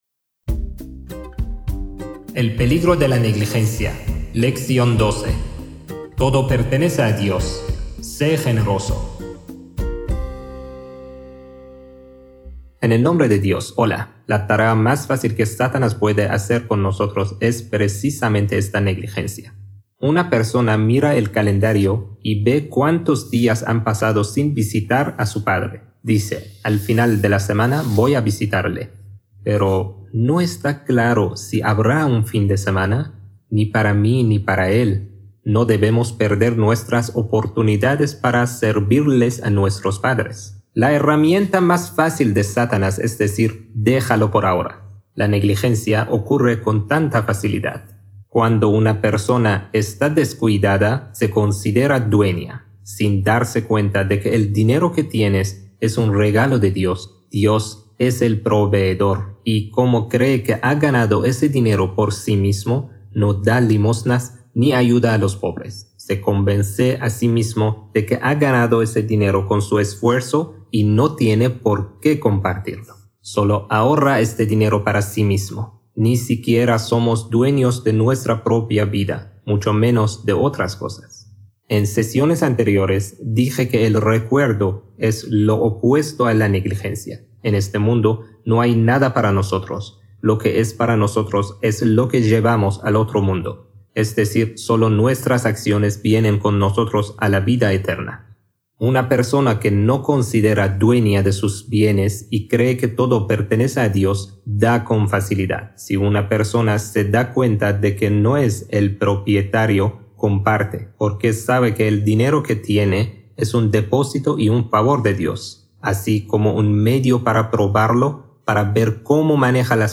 A menudo, la negligencia nos hace olvidar que nada nos pertenece realmente. En esta lección, exploramos cómo el apego a los bienes materiales nos aleja del propósito real de la vida y nos impide compartir con los demás.